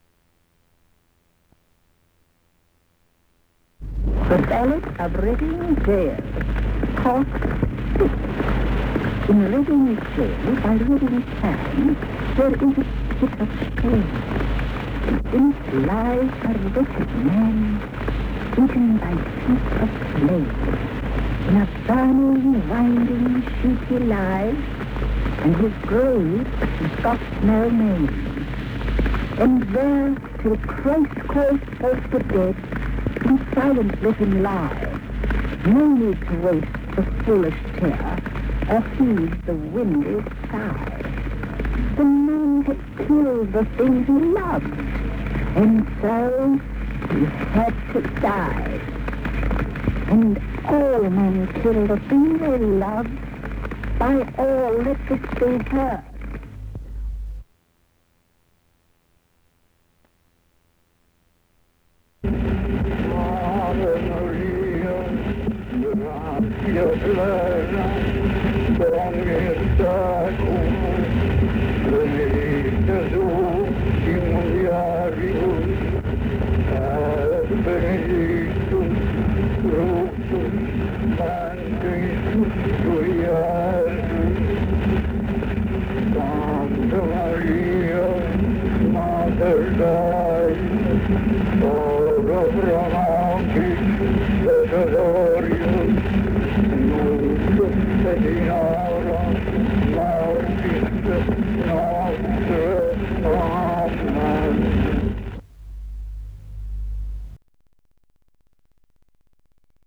Oscar Wilde reads from his poem, The Ballad of Reading Gaol